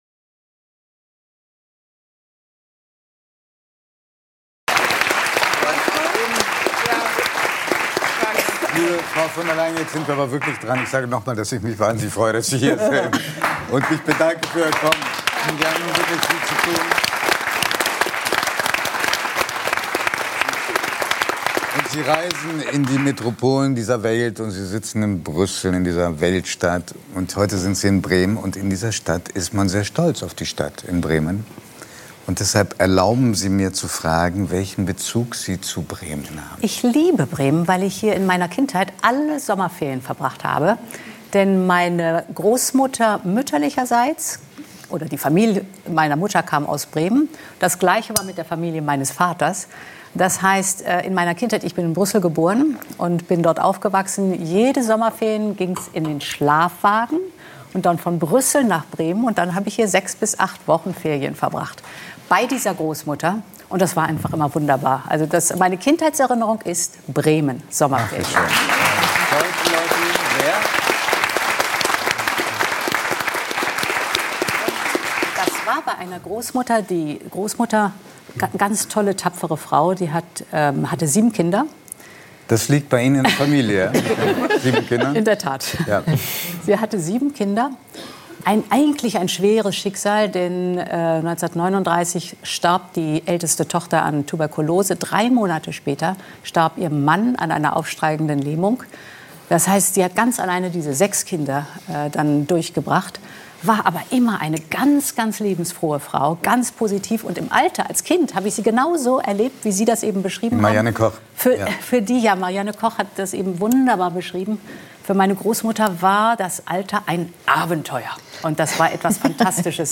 Ursula von der Leyen – EU-Kommissionspräsidentin ~ 3nach9 – Der Talk mit Judith Rakers und Giovanni di Lorenzo Podcast